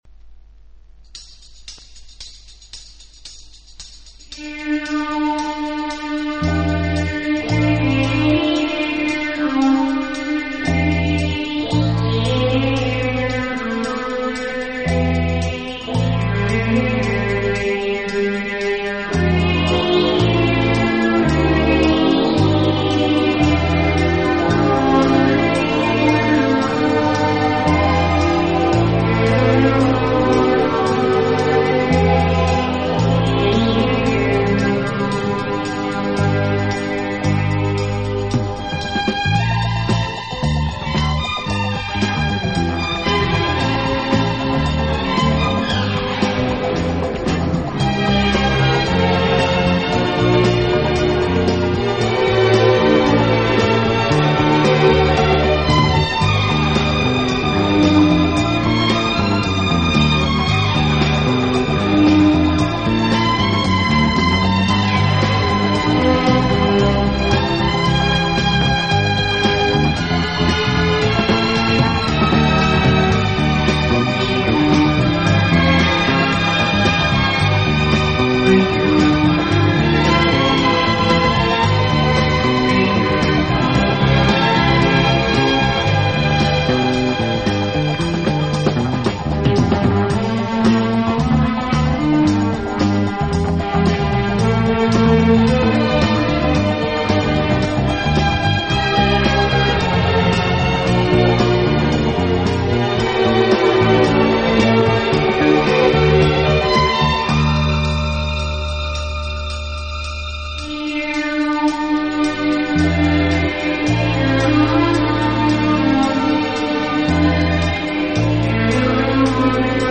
Genres:Pop/Easy Listening,Pop/General